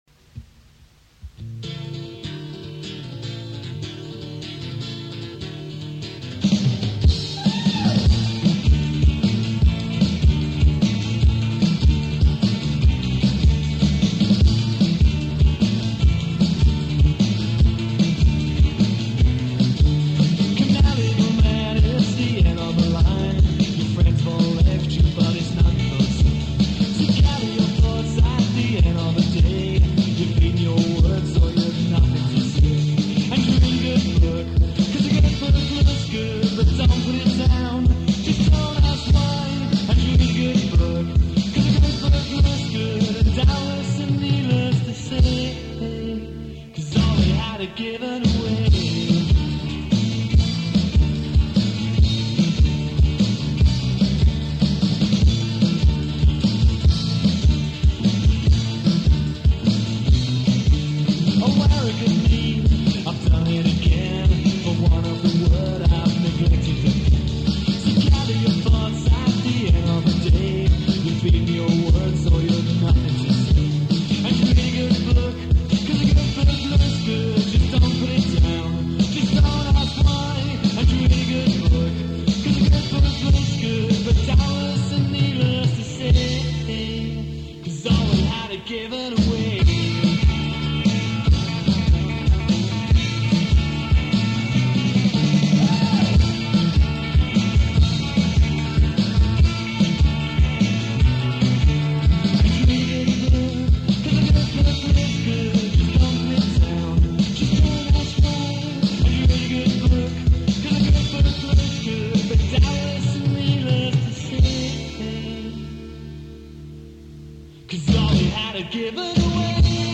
vocals
drums
bass
guitar